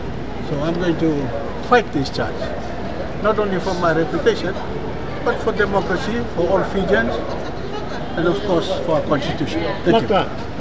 After being granted bail by the Suva Magistrate Court just after lunch today he spoke to the media outside the court.